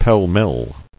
[pell-mell]